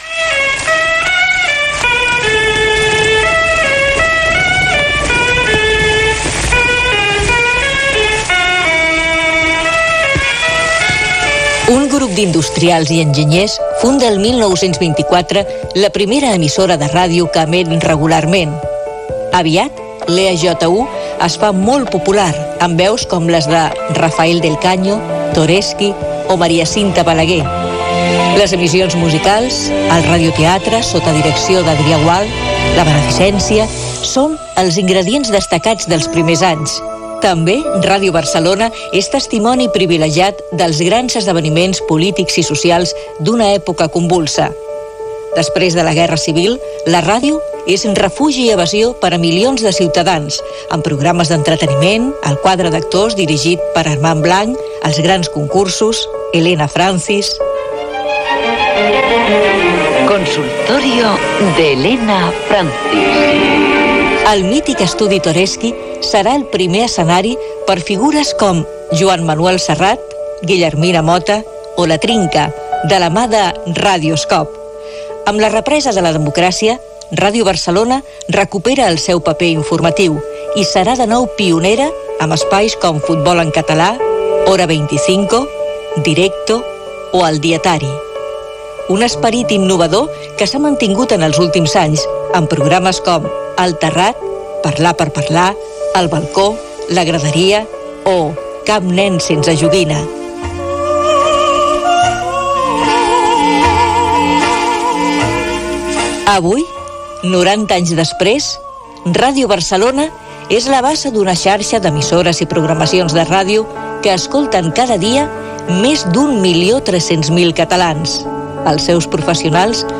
Gala del 90 aniversari de Ràdio Barcelona feta des del Palau de la Música. Repàs a la història de Ràdio Barcelona i entrevista a Xavier Trias, alcalde de la ciutat.
Entreteniment